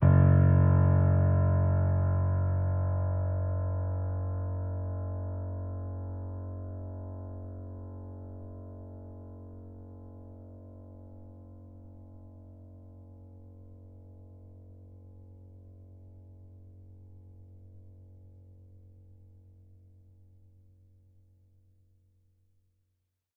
sampler example using salamander grand piano